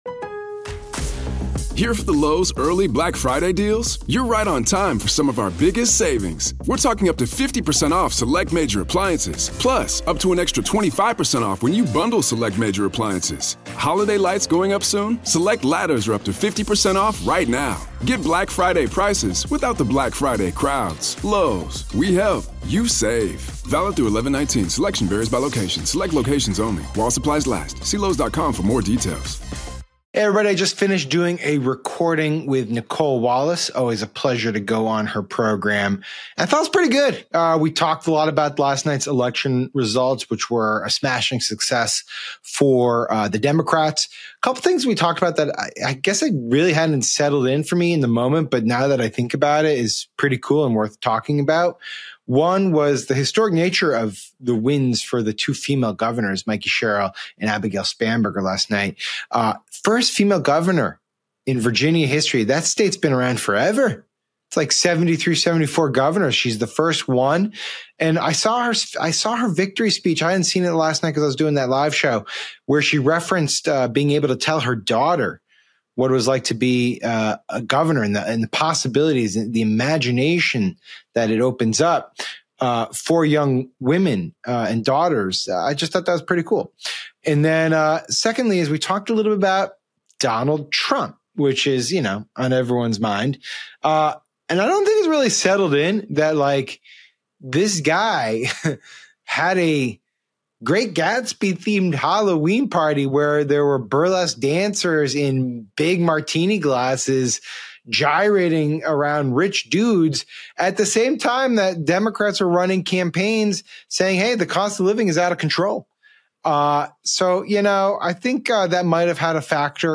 Democrats notch big wins from New Jersey to Virginia, including a historic first woman governor. Sam Stein joins Nicole Wallace to explain how affordability, real-world prices, and a fading culture war reshaped the map.